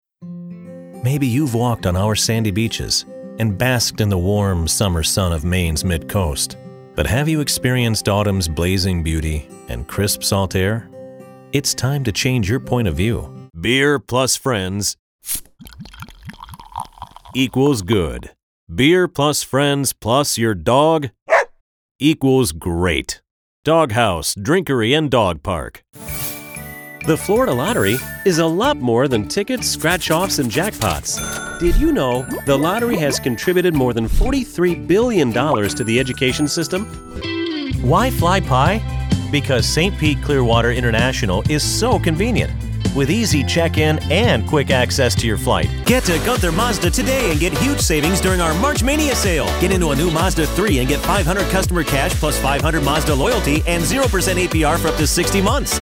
English (American)
Conversational
Authoritative
Smooth